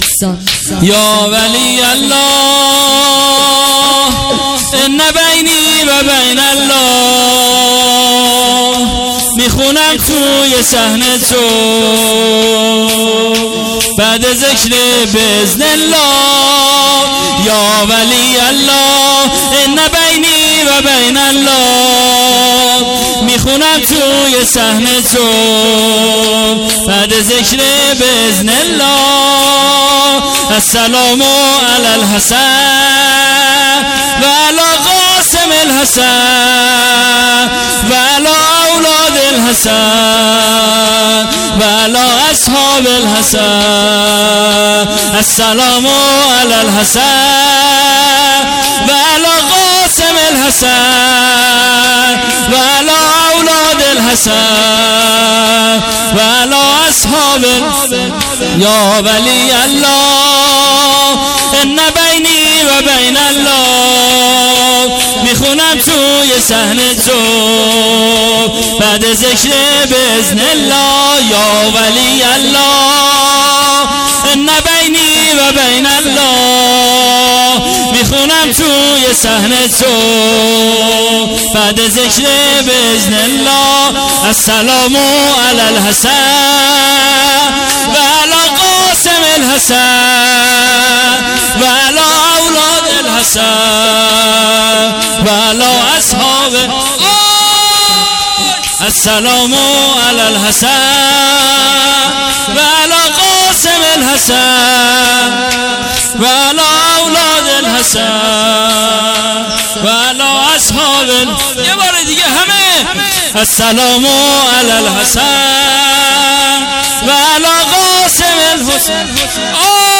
هیئت عاشورا-قم
شهادت حضرت محمد(ص)و امام حسن(ع)۱۳۹۹